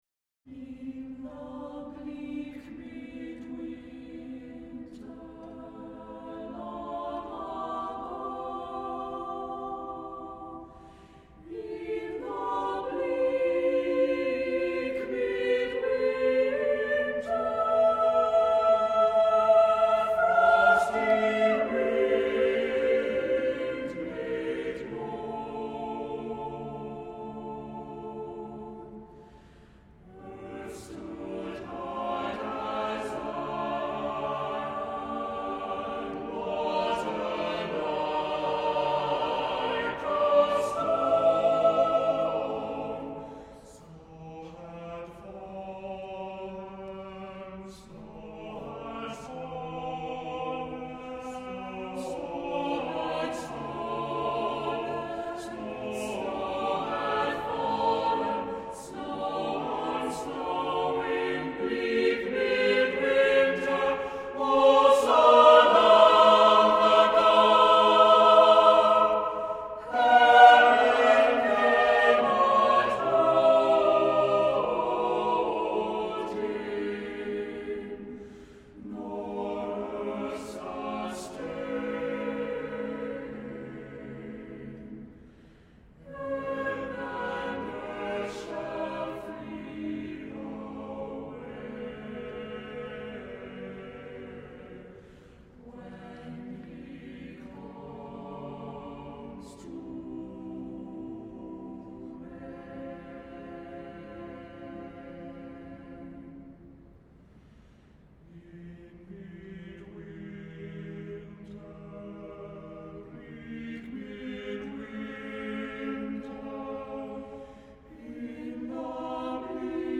Music Category:      Choral